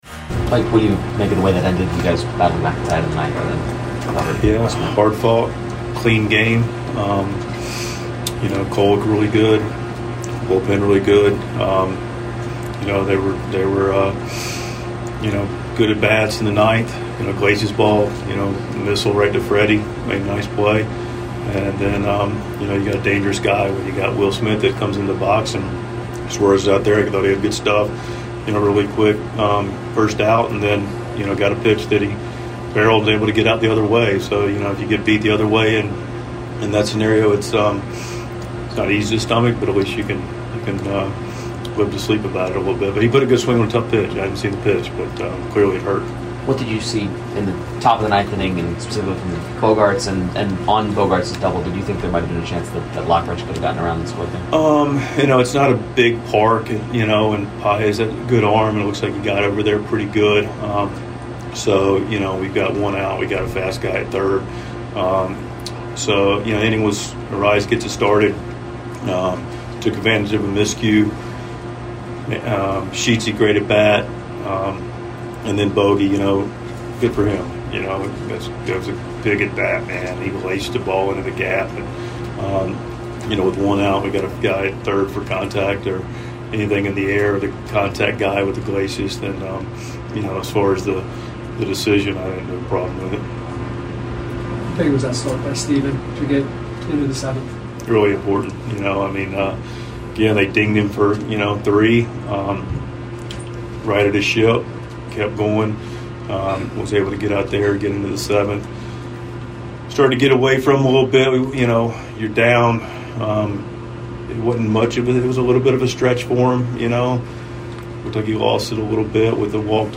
Padres manager Mike Shildt speaks with the media following the team's 4-3 loss to the Dodgers on Wednesday night.